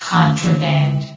S.P.L.U.R.T-Station-13 / sound / vox_fem / contraband.ogg
New & Fixed AI VOX Sound Files